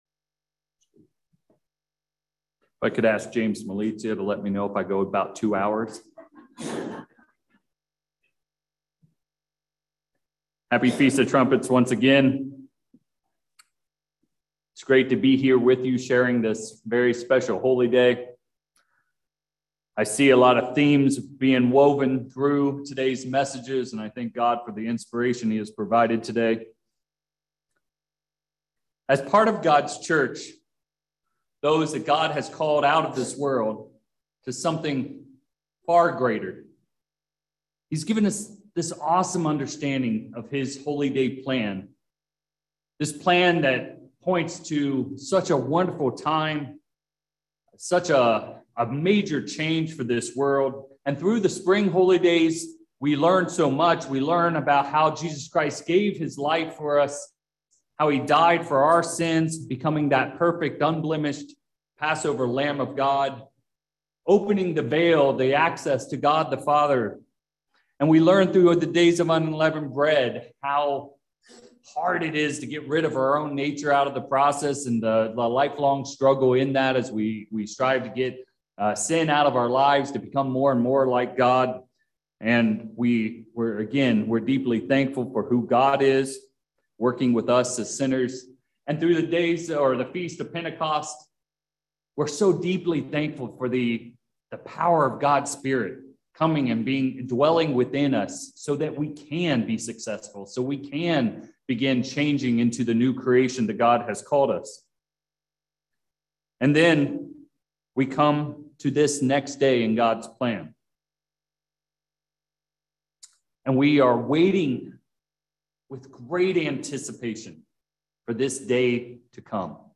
In this sermon, we’ll dive into these two themes of “war” and “assembling” as they relate to the prophetic meaning of the Feast of Trumpets.